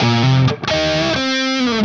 AM_HeroGuitar_130-C02.wav